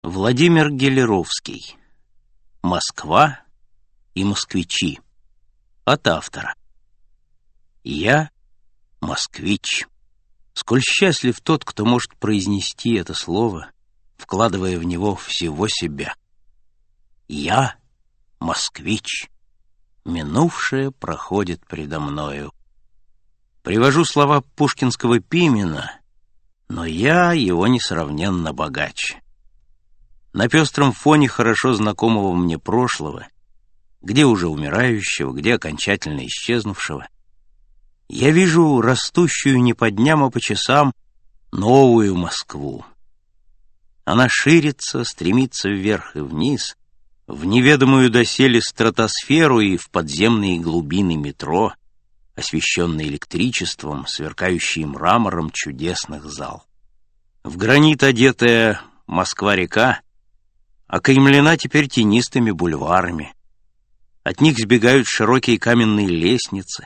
Аудиокнига Москва и москвичи | Библиотека аудиокниг
Прослушать и бесплатно скачать фрагмент аудиокниги